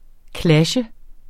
Udtale [ ˈklaɕə ]